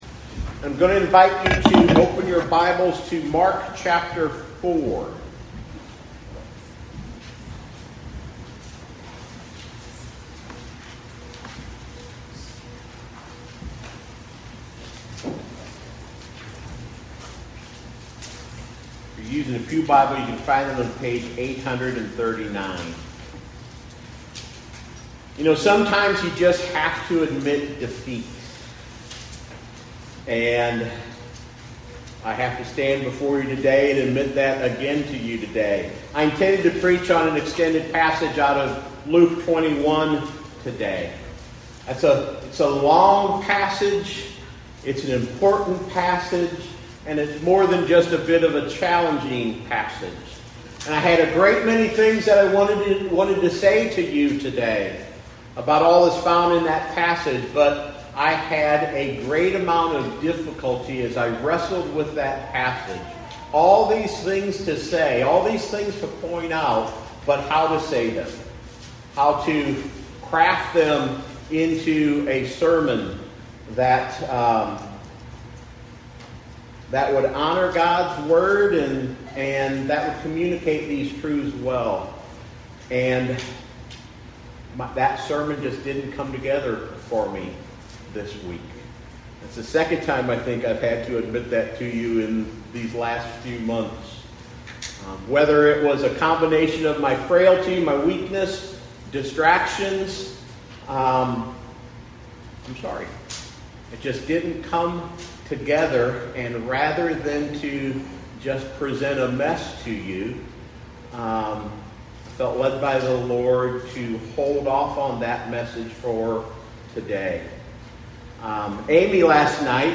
September 14, 2025, New Port Presbyterian Church
Bible verses for sermon: Mark 4:35-41Sermon: Who Is This Jesus?